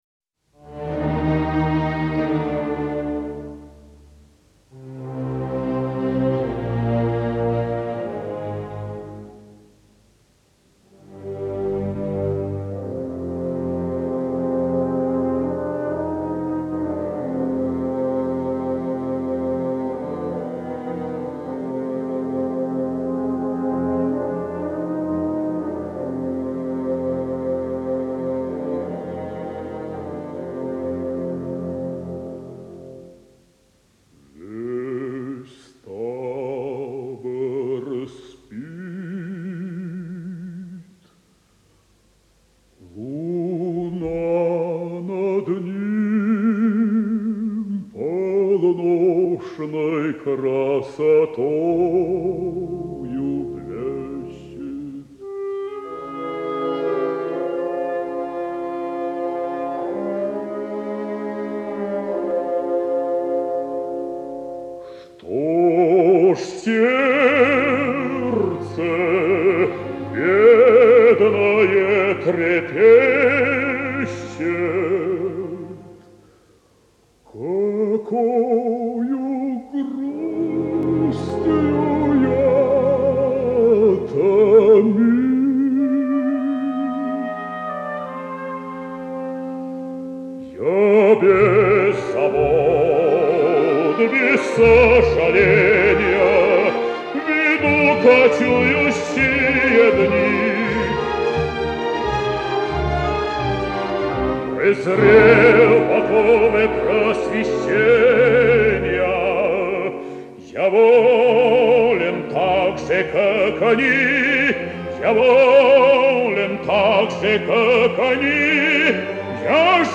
Дмитрий Гнатюк - Каватина Алеко (С.В.Рахманинов. Алеко)